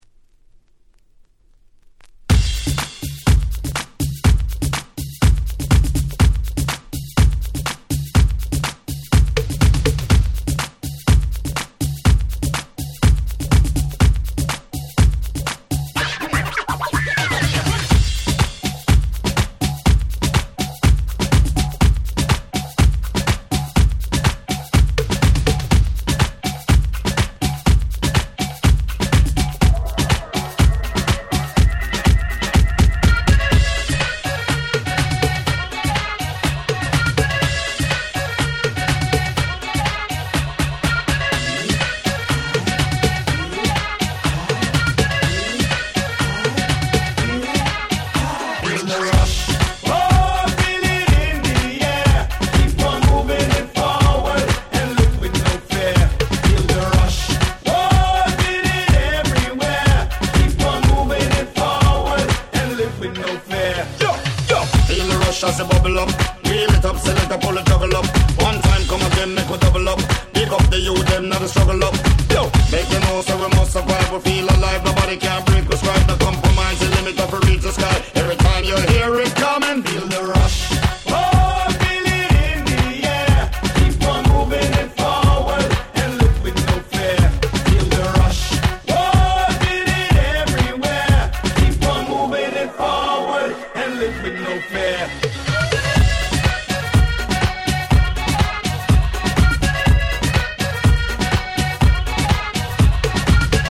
White Press Only Remix !!
どのRemixもアゲアゲ仕様で非常に使えます！
レゲエ